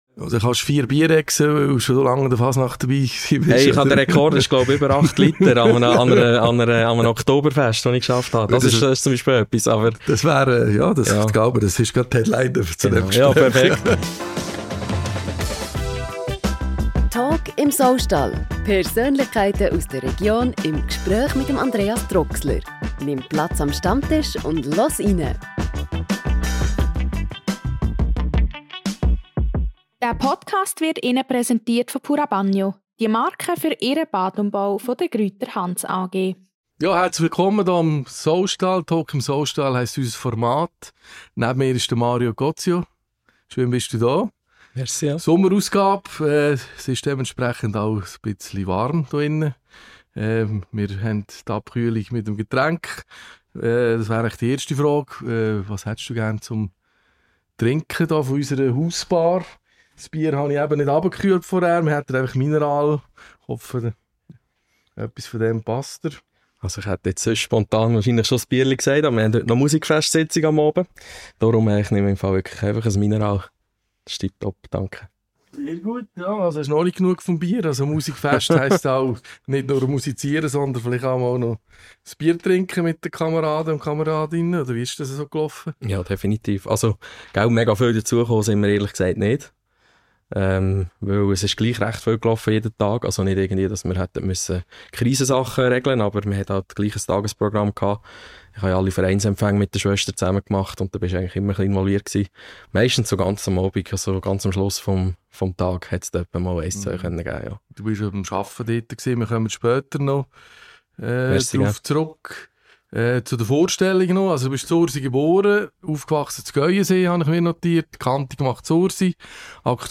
Beschreibung vor 8 Monaten Im monatlichen Podcast im ehemaligen Saustall in Nottwil erfährst du mehr über die Menschen aus der Region.